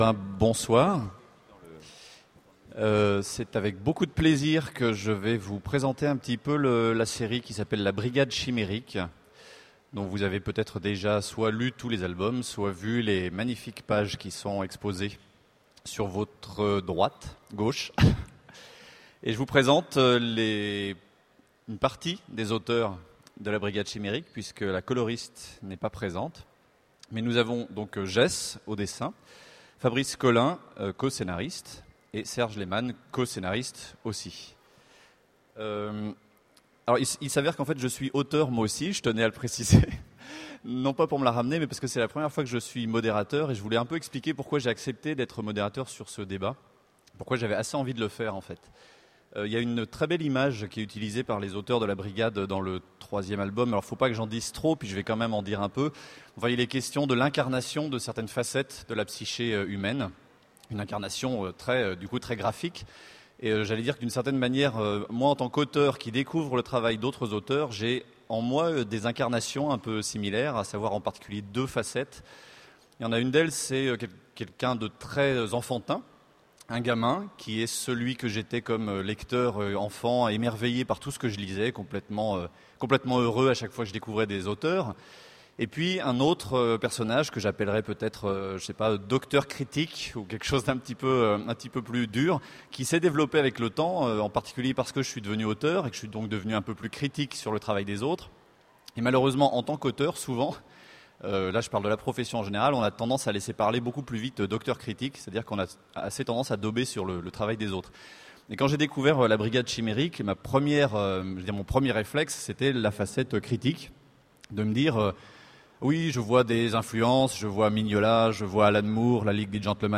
Voici l'enregistrement de la Conférence sur "La Brigade Chimérique" aux Utopiales 2009.